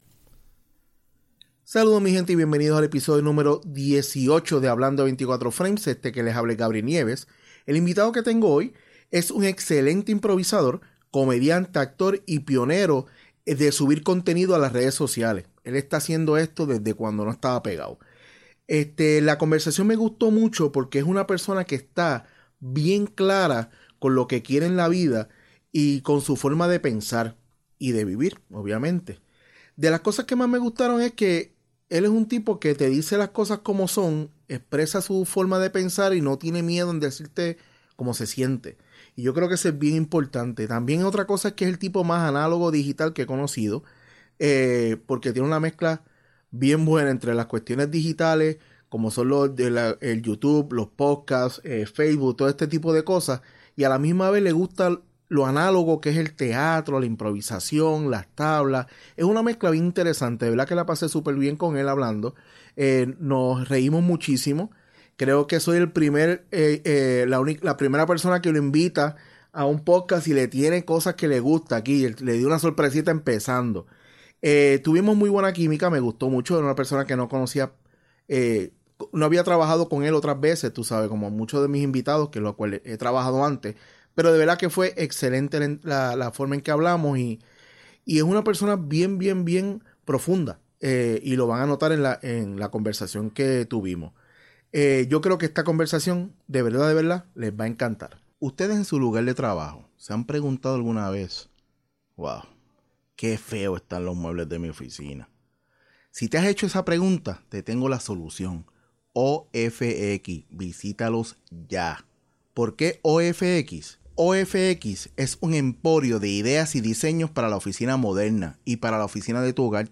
Espero que les encante esta conversación...